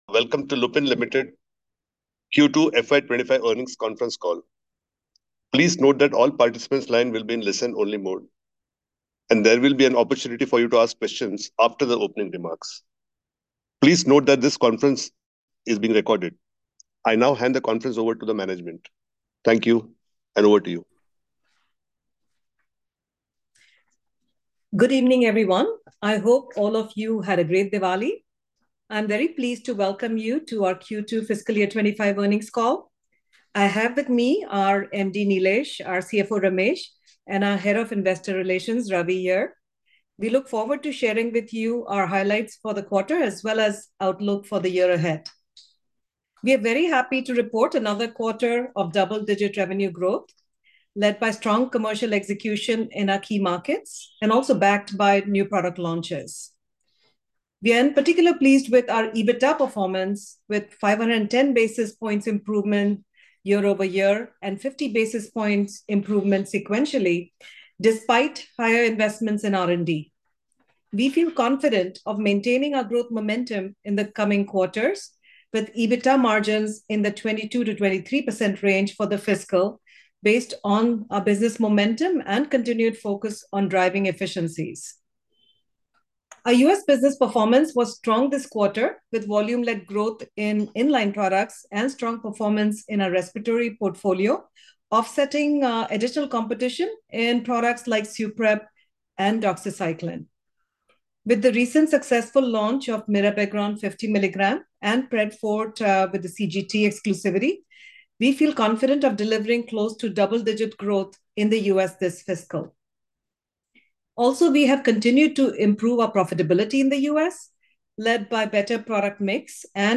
q2-fy25-earnings-call.mp3